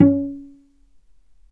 healing-soundscapes/Sound Banks/HSS_OP_Pack/Strings/cello/pizz/vc_pz-C#4-mf.AIF at bf8b0d83acd083cad68aa8590bc4568aa0baec05
vc_pz-C#4-mf.AIF